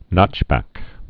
(nŏchbăk)